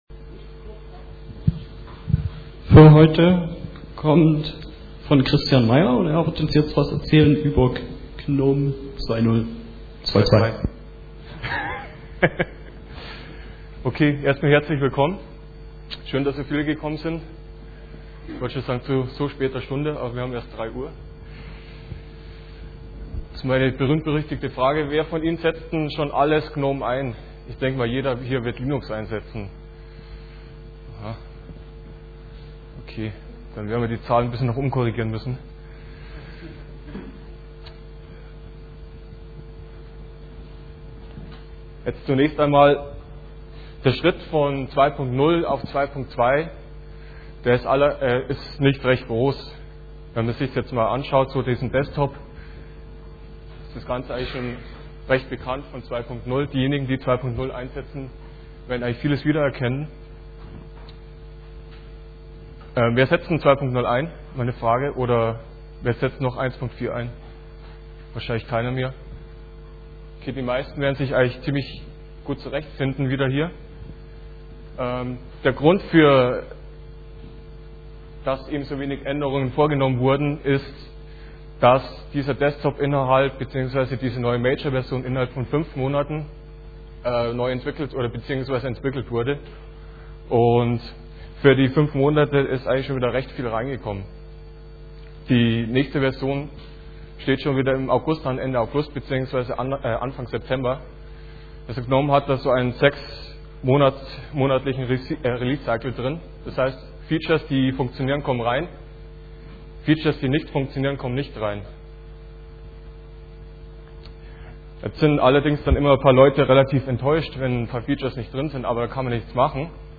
16kbps Vortragsmittschnitt als MP3